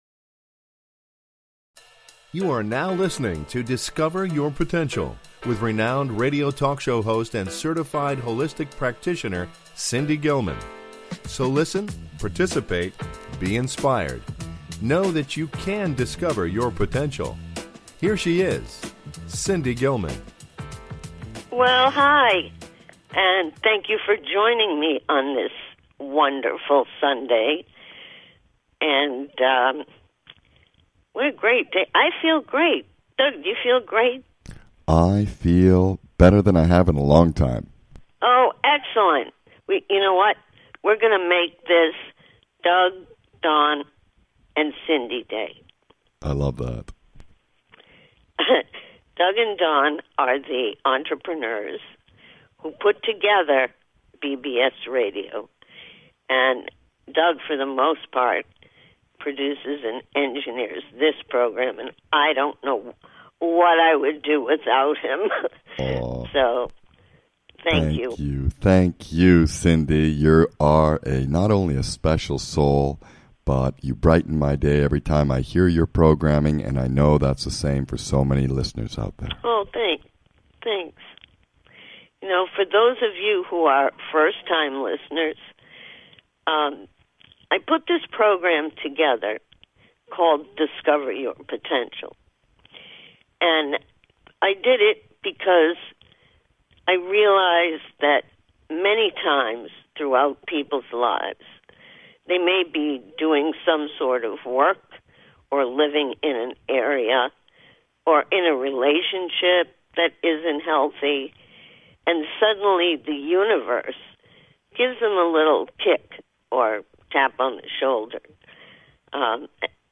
The program is informative, inspiring, motivating, spiritual, and yes, with some humor.
Talk Show